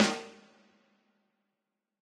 snare.ogg